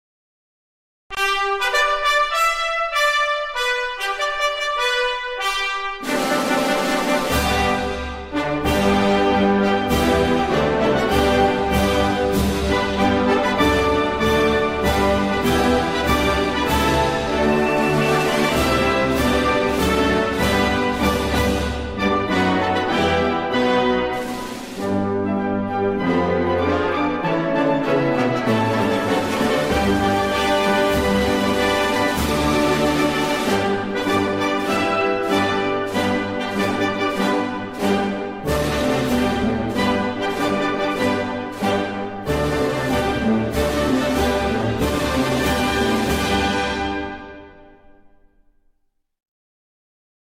Chinese_anthem.mp3